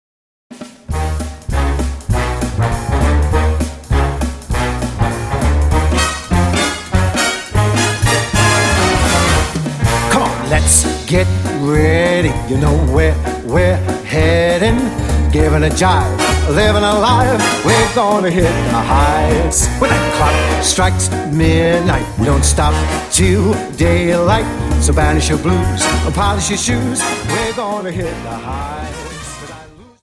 Dance: Quickstep 50